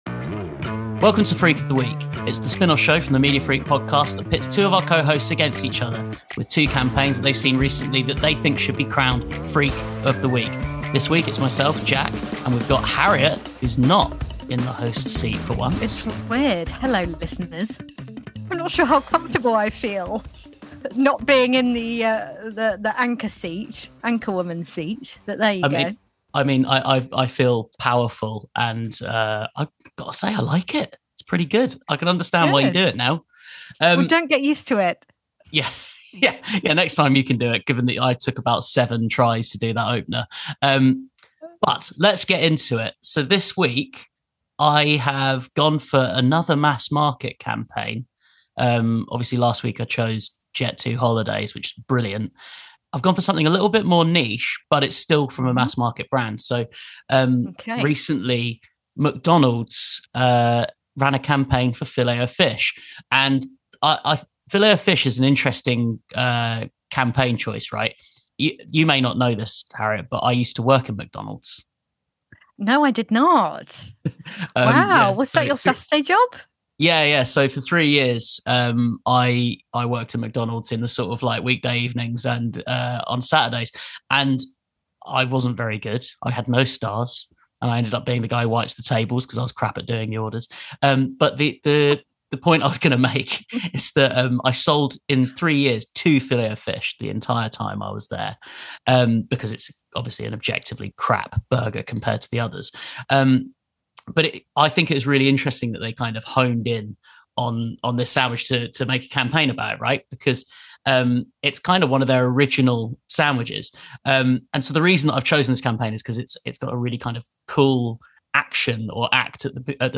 Freak of the week is a series of short punchy episodes that see two MFP hosts go head to head with a campaign that they've seen recently. They'll talk you through it and then fight it out to determine which campaign is best.